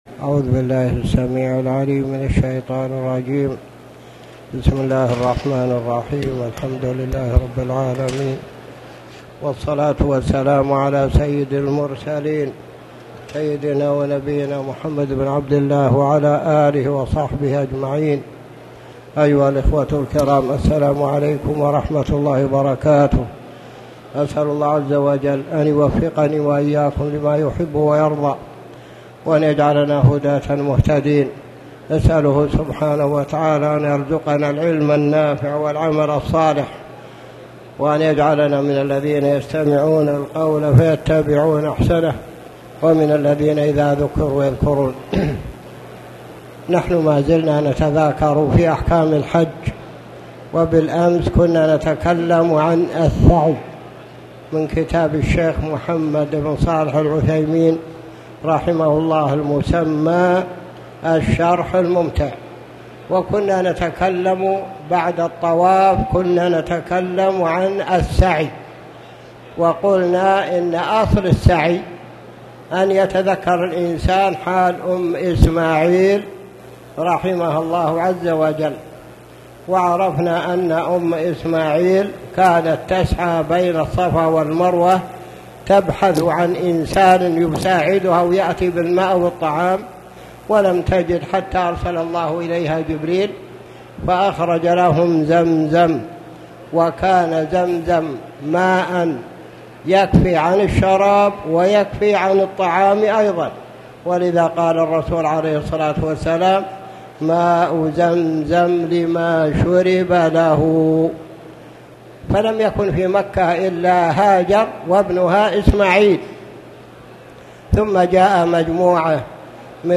تاريخ النشر ٢٧ ذو الحجة ١٤٣٨ هـ المكان: المسجد الحرام الشيخ